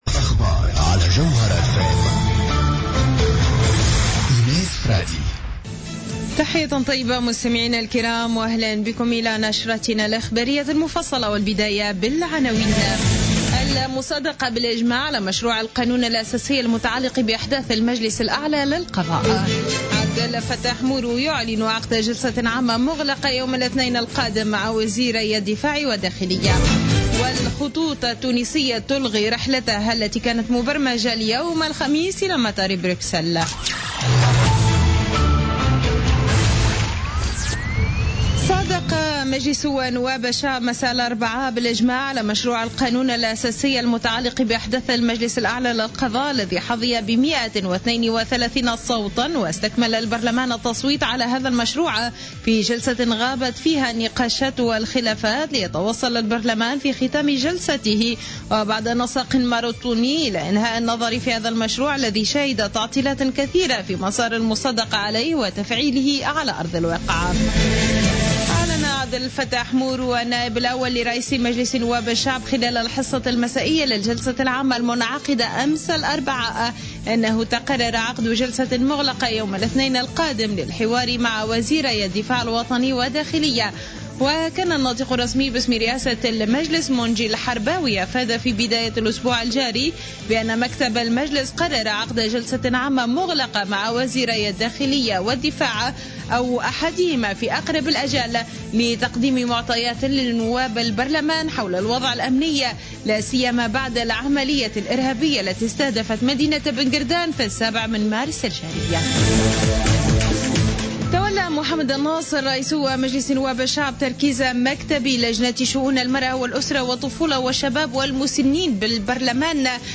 نشرة أخبار منتصف الليل ليوم الخميس 24 مارس 2016